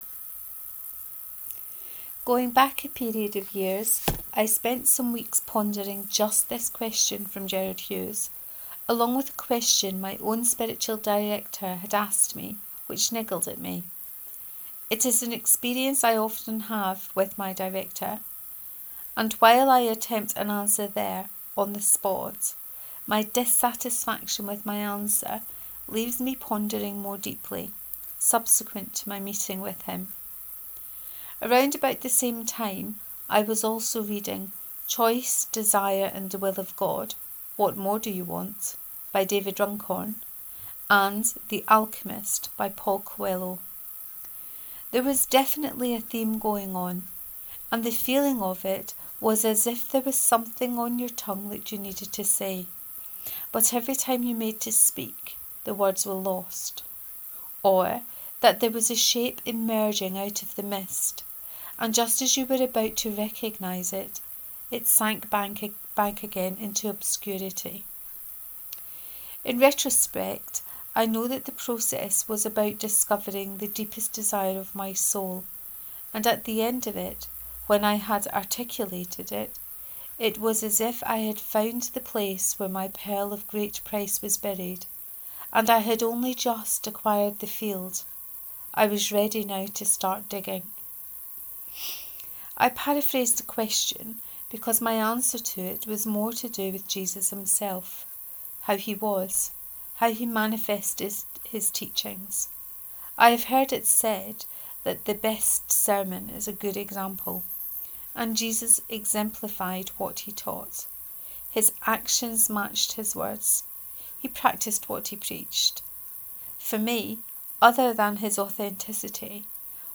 What do you find attractive about Jesus? 2: Reading of this post.